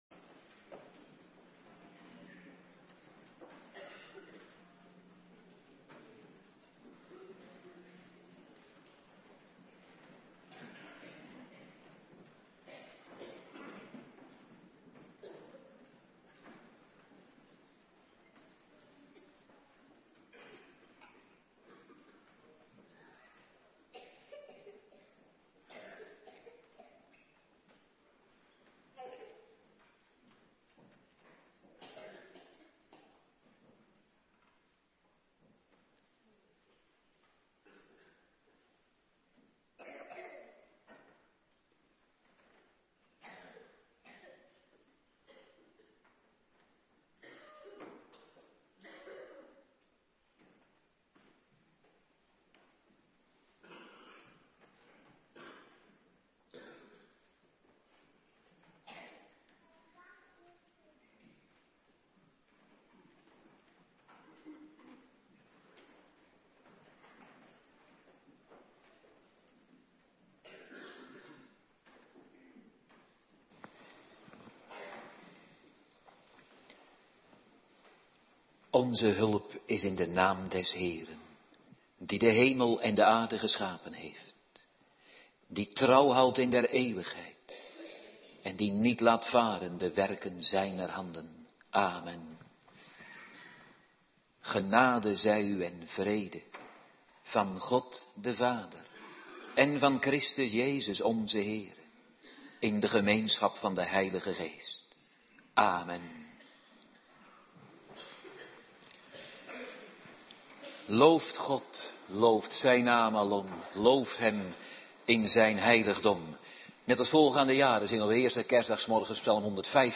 Morgendienst eerste kerstdag
09:30 t/m 11:00 Locatie: Hervormde Gemeente Waarder Agenda